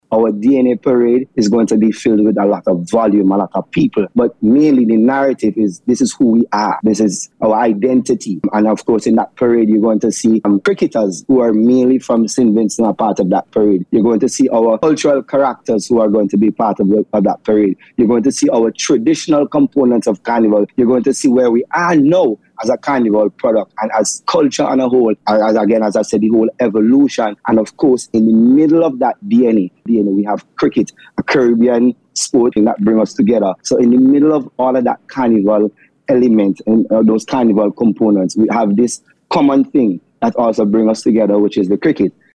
speaking on NBC Radio recently, where he outlined some of the elements of the parade.